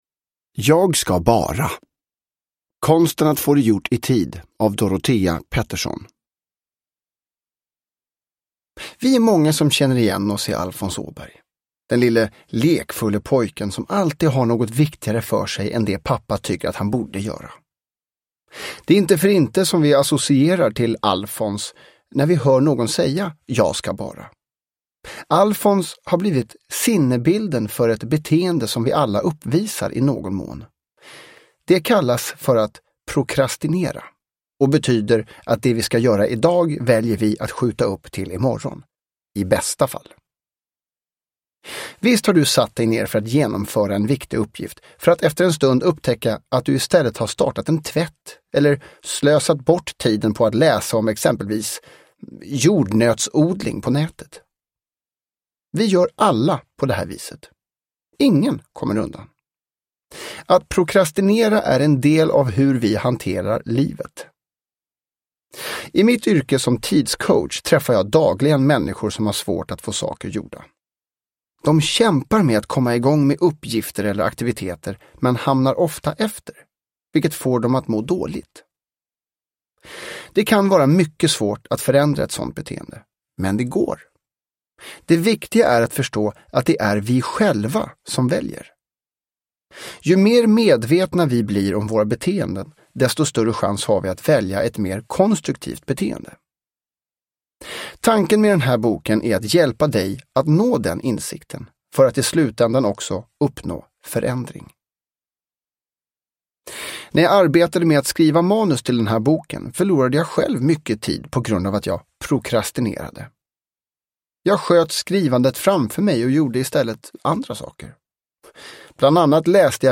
Jag ska bara... : konsten att få det gjort i tid – Ljudbok – Laddas ner